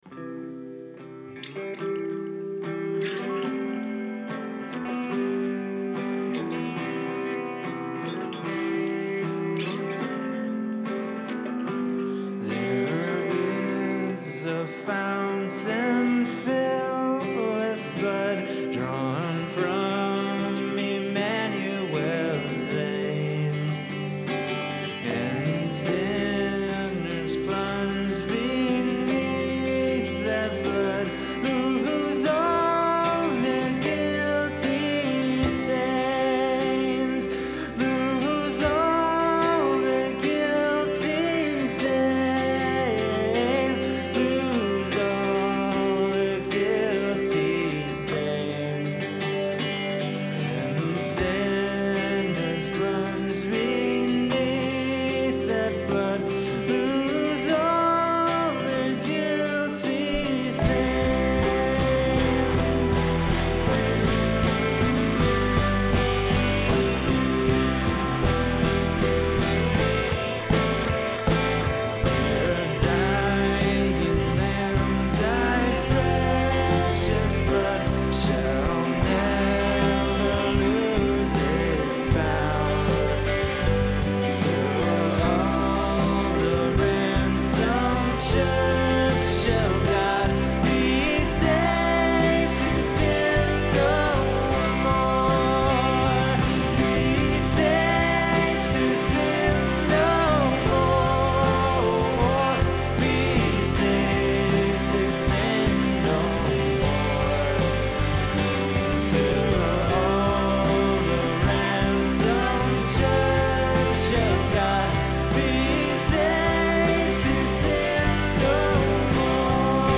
Performed live at Terra Nova - Troy on 3/7/10.